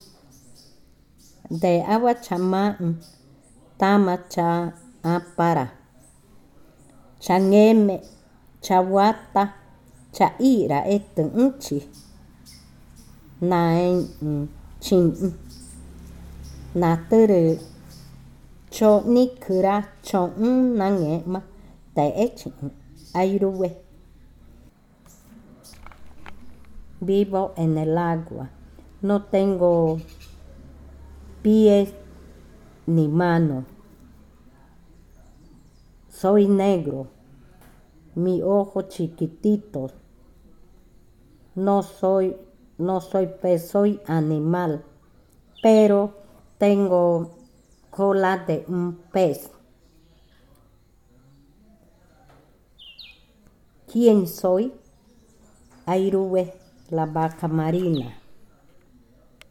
Adivinanza 31. Vaca marina
Cushillococha